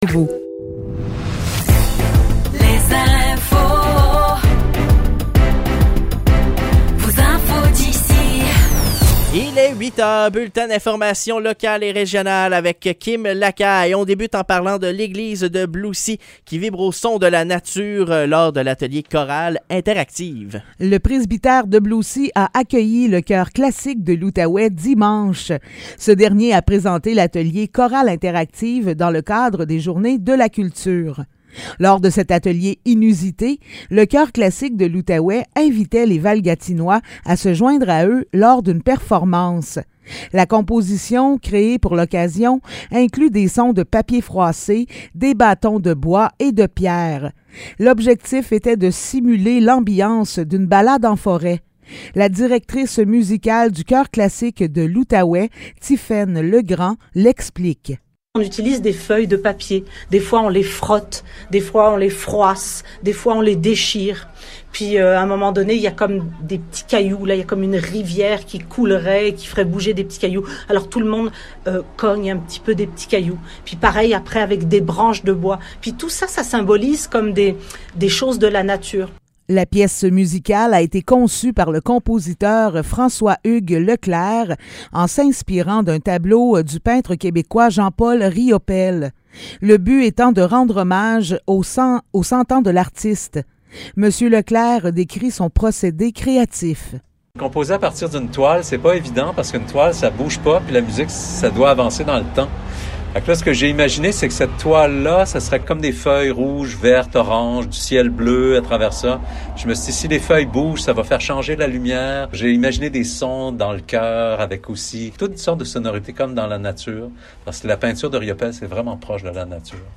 Nouvelles locales - 2 octobre 2023 - 8 h